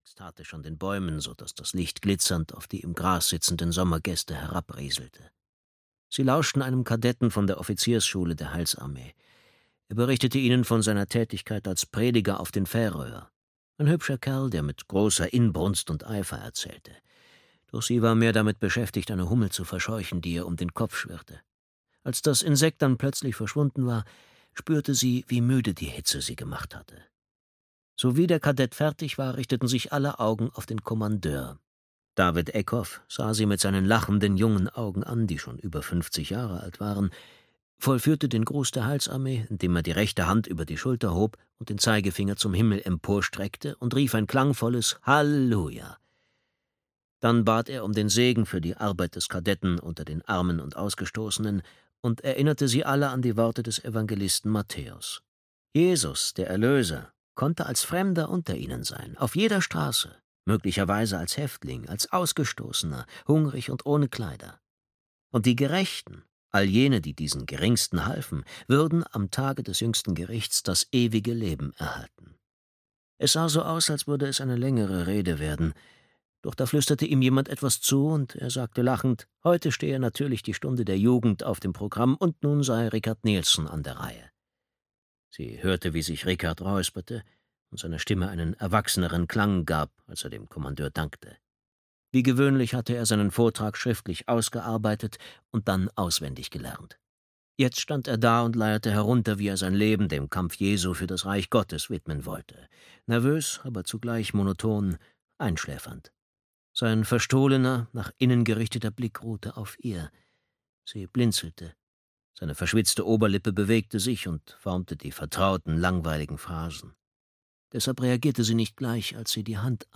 Erlöser (DE) audiokniha
Ukázka z knihy